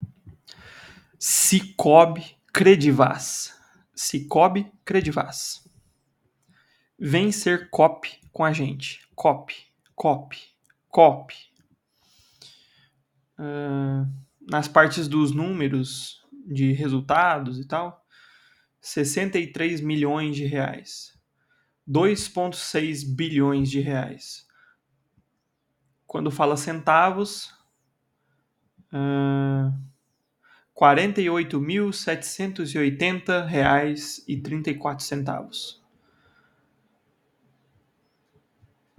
Narração SICOOB